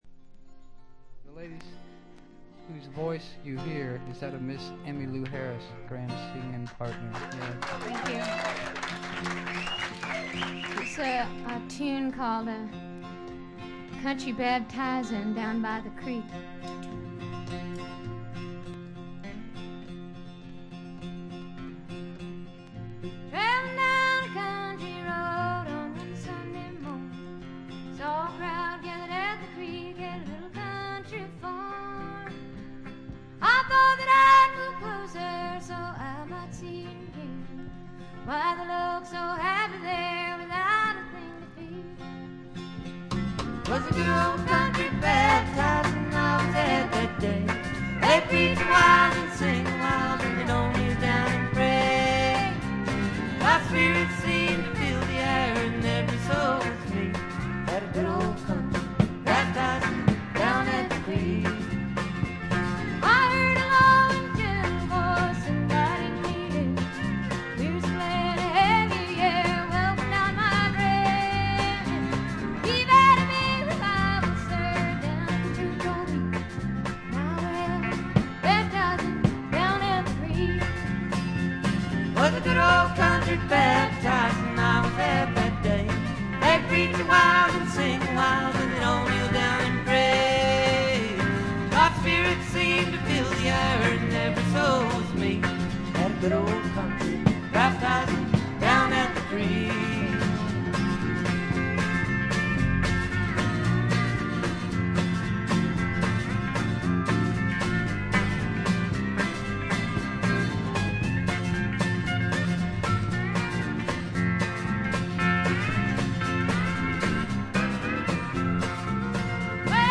ホーム > レコード：カントリーロック
ほとんどノイズ感無し。
試聴曲は現品からの取り込み音源です。